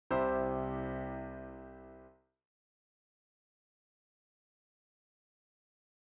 Root Position Chords
In the first column, you’ll see the standard C chord, with C as the lowest-sounding note.
root_pos_chord.mp3